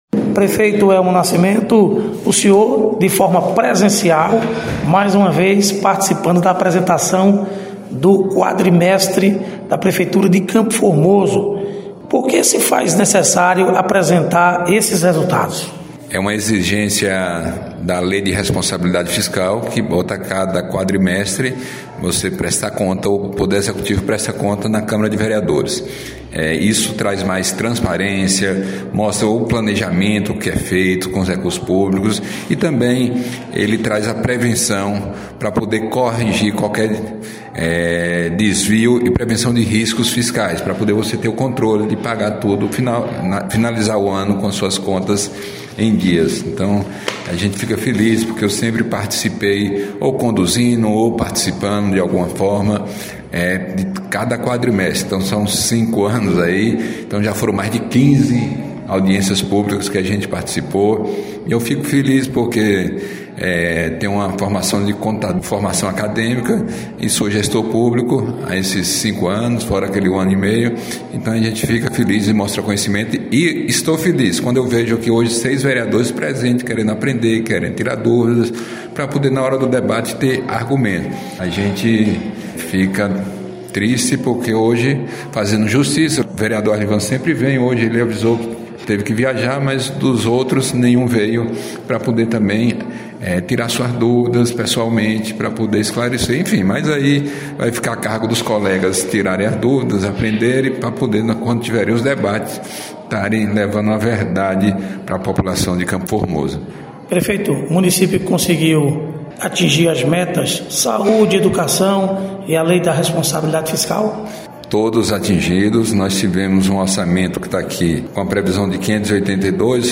Prefeito de CFormoso, Elmo Nascimento- Apresentação do Quadrimestre da prefeitura Municipal